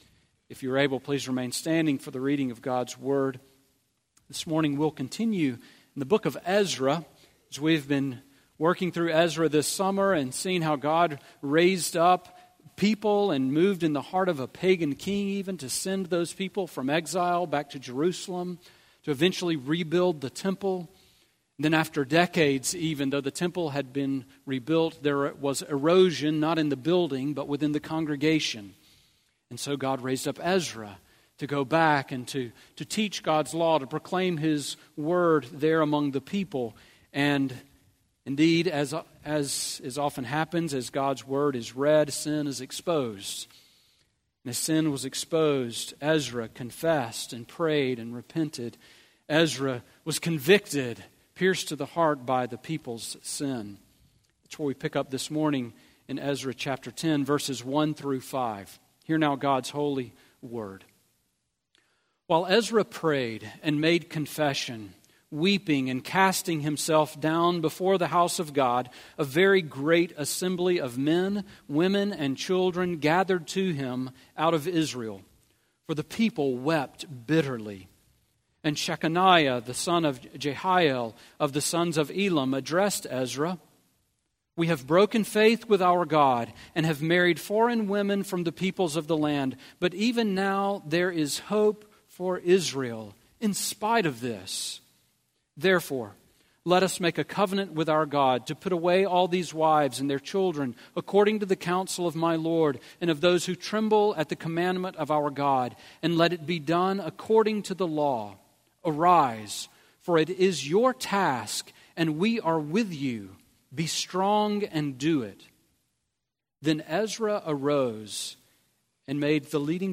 Sermon on EZRA 10:1-5 from August 13